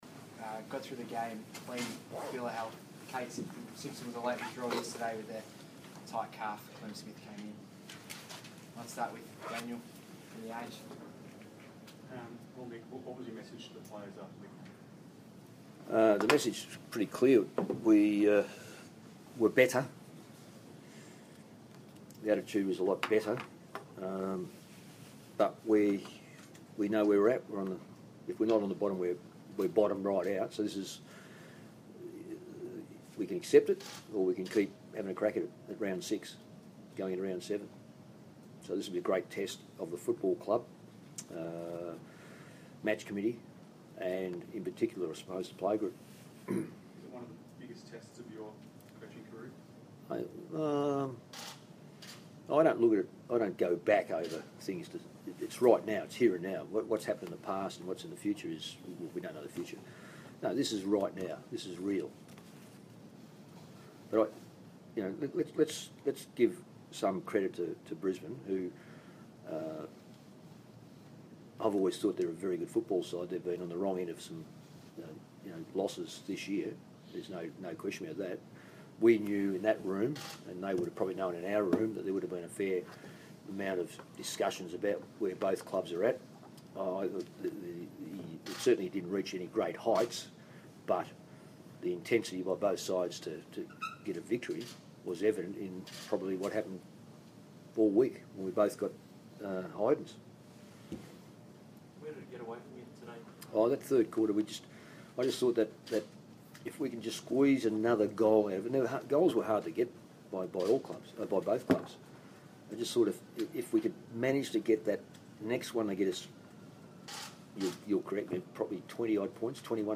Round 6 post-match press conference
Coach Mick Malthouse speaks to the media after Carlton's 9-point loss to the Lions.